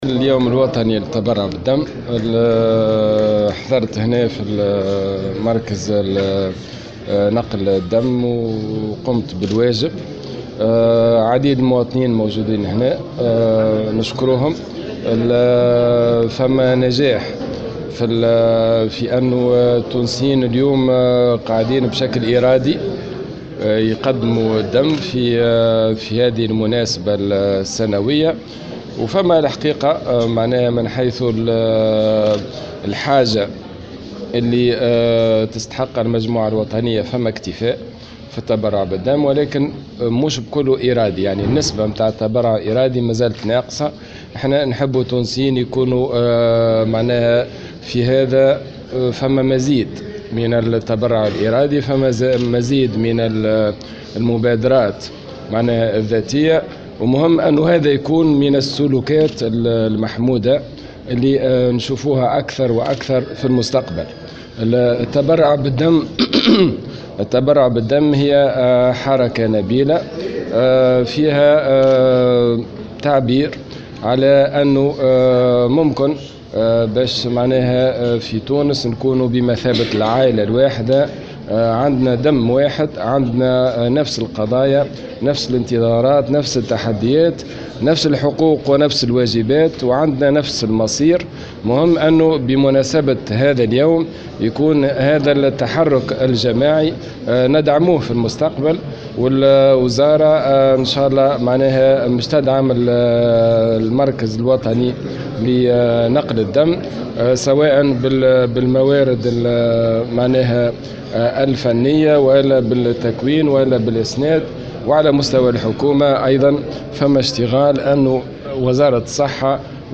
وشدّد في تصريح لمراسلة "الجوهرة أف أم" على أهمية المبادرات الذاتية في هذا المجال، معتبرا التبرع حركة نبيلة.
وجاءت هذه التصريحات بمناسبة اشرافه على فعالية اليوم الوطني للتبرع بالدم يوم 8 أفريل من كل عام، أدى خلالها زيارة ميدانية للمركز الوطني لنقل الدم بالعاصمة حيث عاين ظروف التبرع بالدم بقسمي الدم الكامل والتبرعات الخاصة.